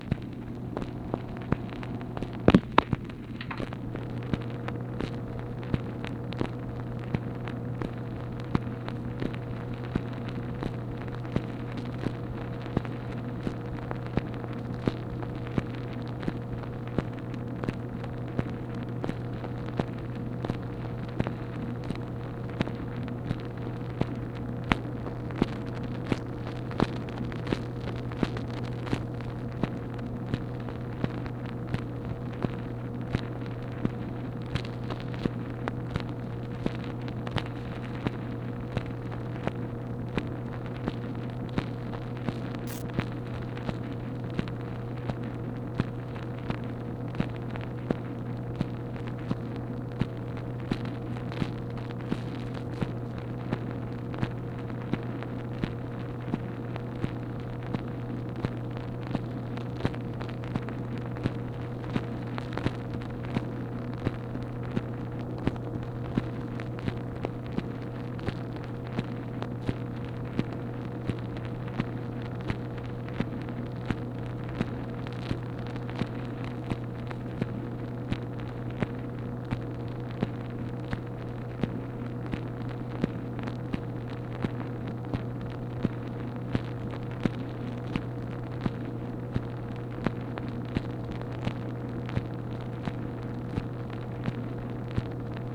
MACHINE NOISE, May 14, 1965
Secret White House Tapes